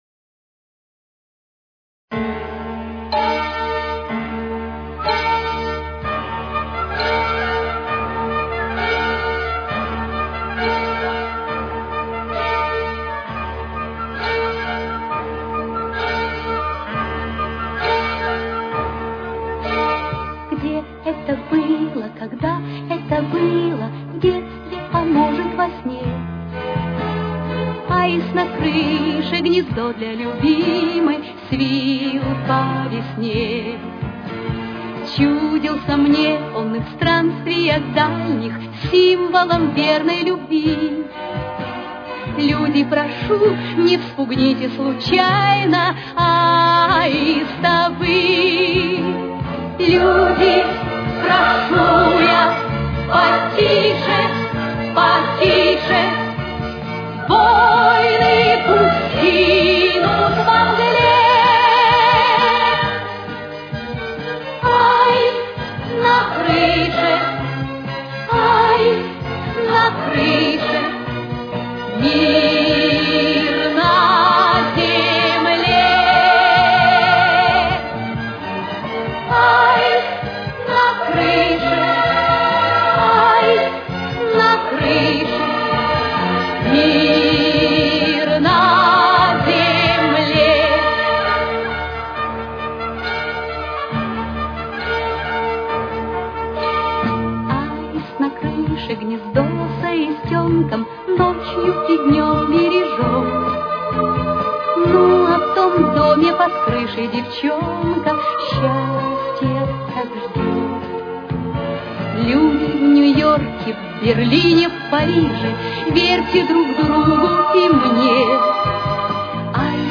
Ре минор. Темп: 134.